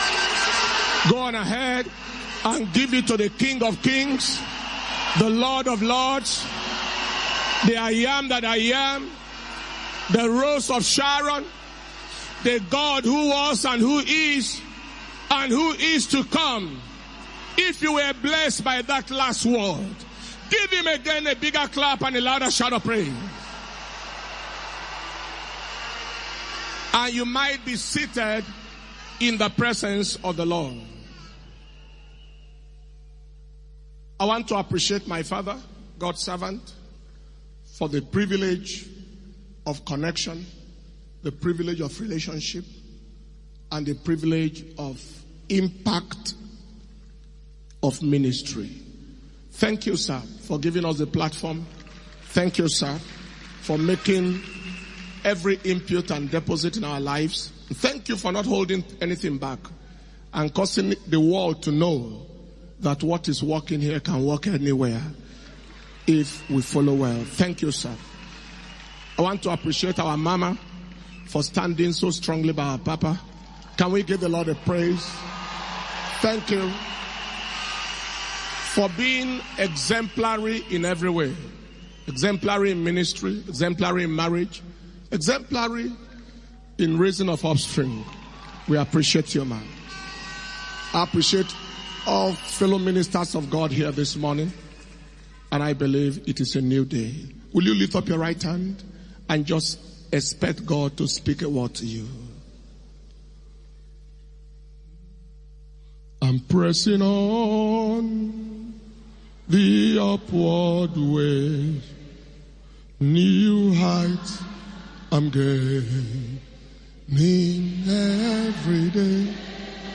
Day 3 Shiloh 2021 Hour of Visitation 9th December 2021